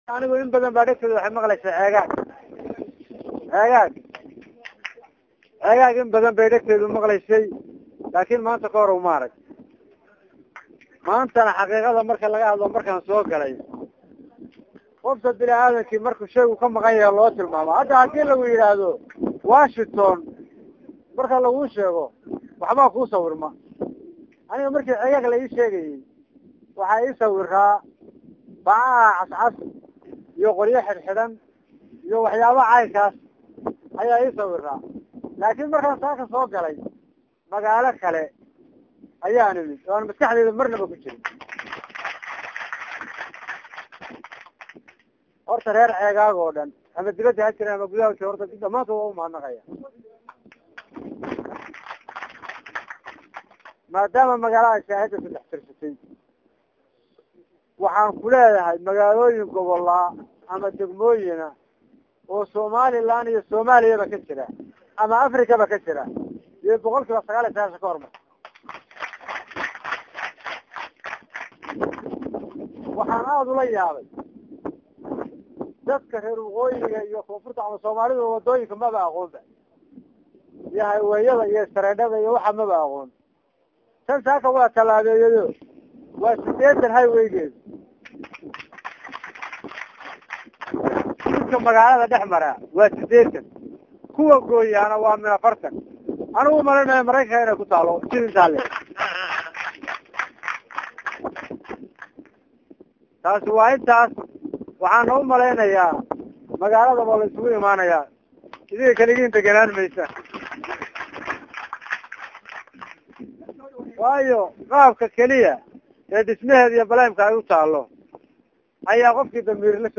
Gudoomiye K/xigeenka Gobolka Sool Maxamed Cabdi Dhible
Ugu danbaytii waxaa xaruntaasi caafimaad ee laga hirgaliyey degmada ceegaag xadhiga ka jaray Gudoomiye kuxigeenka gobolkaasi sool Maxamed Cabdi jibriil(indhil) Waxna uuna halkaasi ka soo jeediyey khudbad dheer isagoo sheegay inuu yahay safarkii ugu horeeyey oo uu Ceegaag ku yimaado waxaana uu intaasi raaciyey inuu magaalada moodayey tuulo balse  uu yimid magaalo caasimad ah, aadna faraxsan ayuu yidhi gudoomiye kuxigeenka gobolkaasi sool, intaasi kadib ayuu madasha ka sheegay inuu aad ugu faraxsan yahay horumarka balaadhan ee  Degmada Ceegaag ka socda isagoo dhinaciisa mahad balaadhan u jeediyey Qurba joogta Reer Ceegaag isagoo xusay inay maanta dhisteen magaalo ay ku faanikaraan.